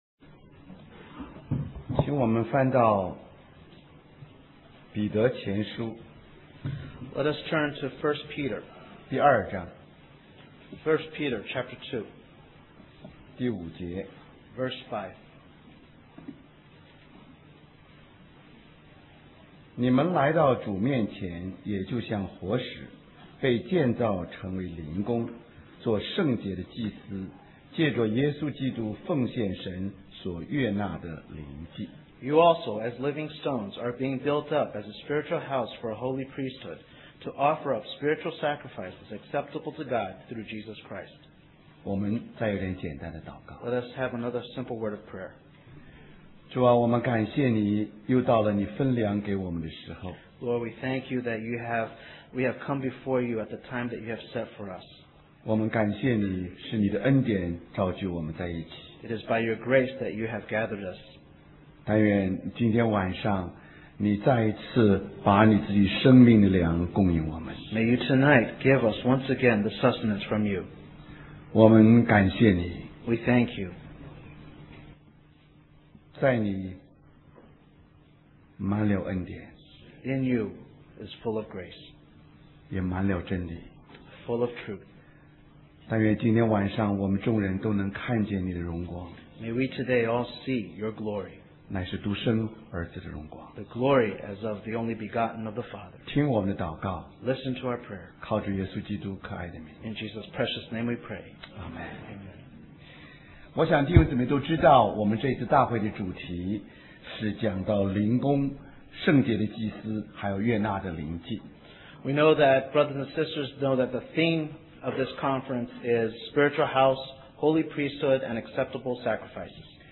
Special Conference For Service, Singapore